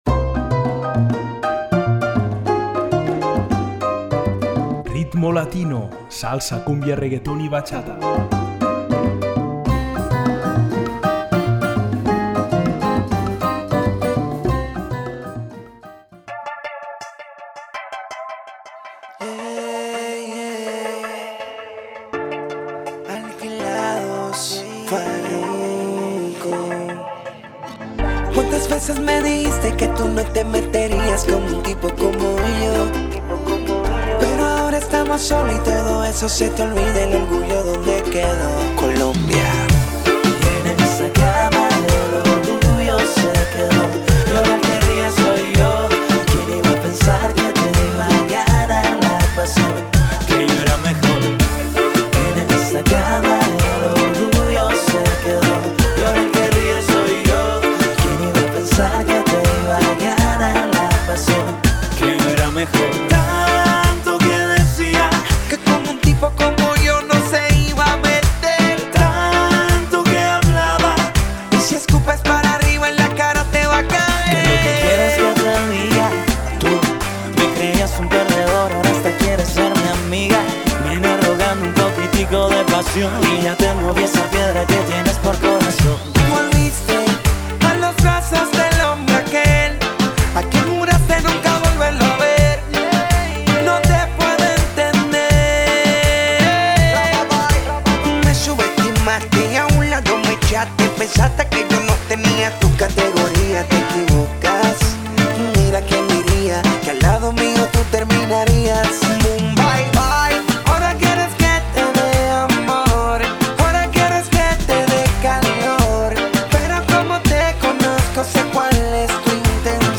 Salsa, cúmbia, reggaeton i batxata